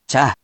We have our computer friend, QUIZBO™, here to read each of the hiragana aloud to you.
#2.) Which hiragana youon do you hear? Hint: 【cha】
In romaji, 「ちゃ」 is transliterated as「cha」which sounds sort of like「chahh」or the British slang 「char」without the schwa or diphthong.